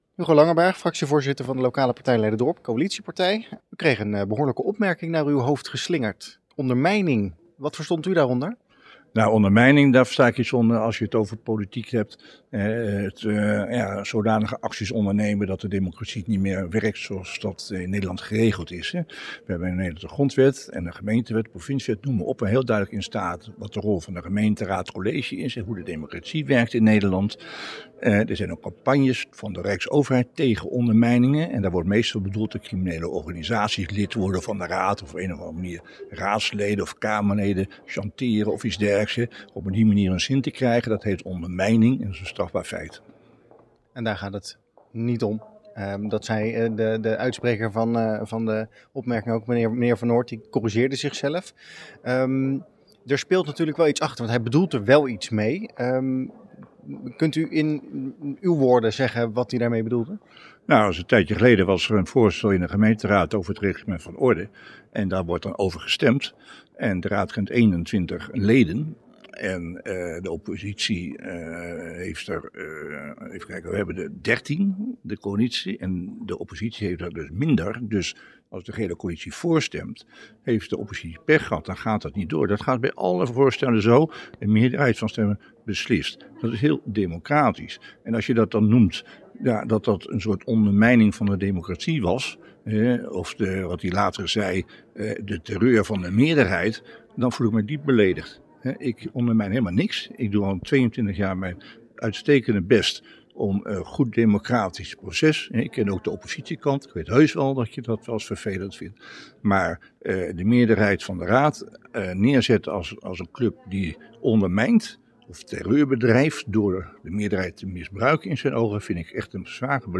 Tijdens het begrotingsdebat vrijdagmiddag ontstond een scherpe discussie tussen VVD-fractievoorzitter Frank van Noort en Hugo Langenberg van de Lokale Partij Leiderdorp (LPL).